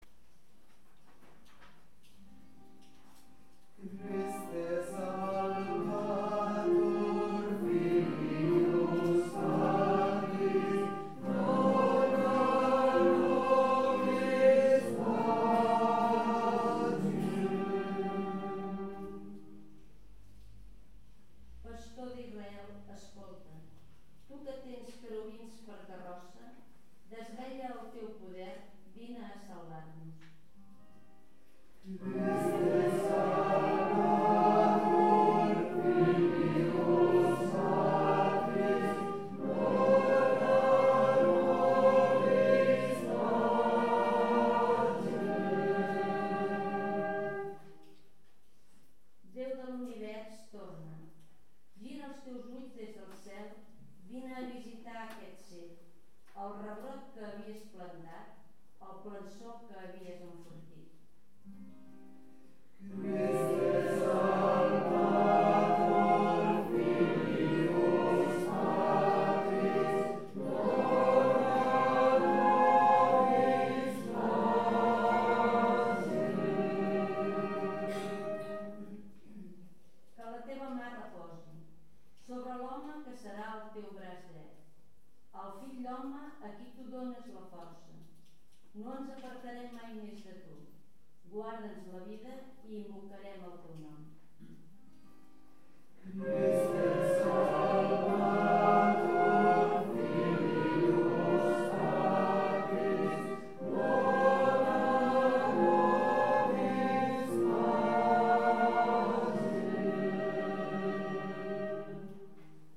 Pregària de Taizé
Capella de les Concepcionistes de Sant Josep - Diumenge 30 de novembre de 2014